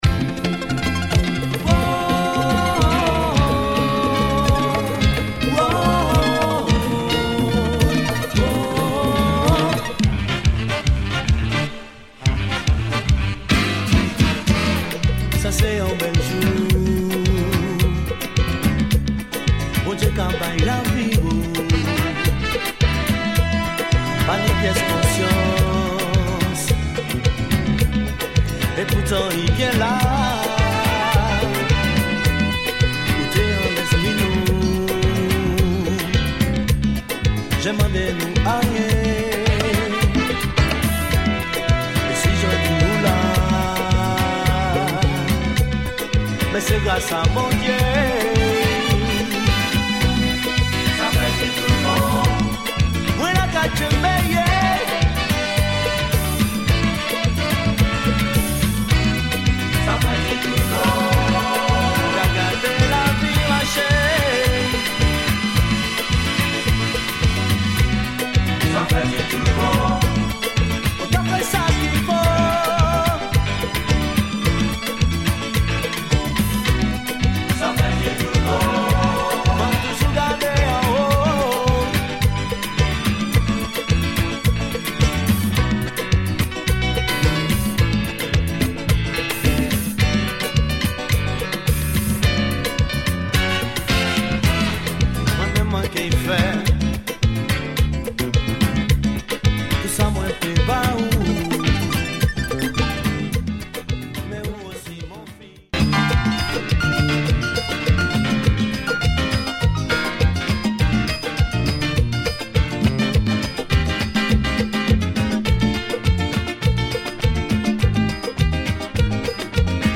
In demand synth West Indian sounds
Caribbean